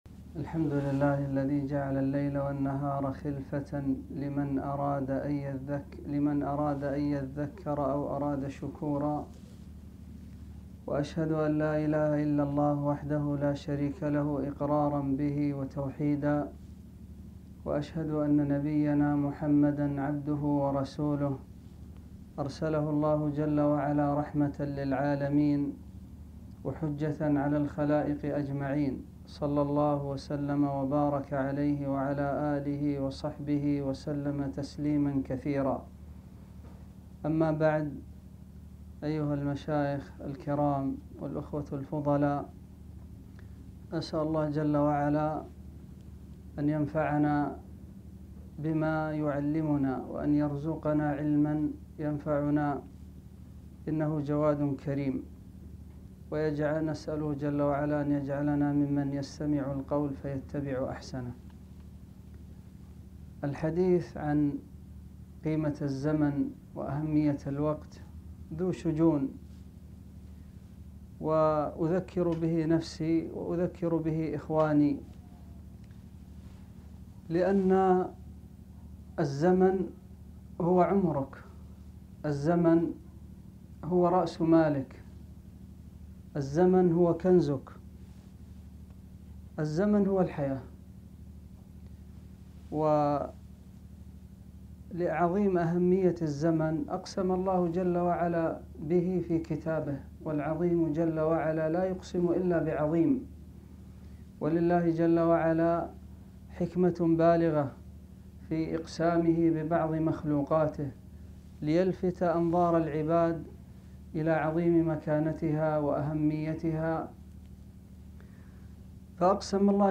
محاضرة - قيمة الزمن